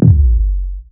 Metro Kicks [Boom].wav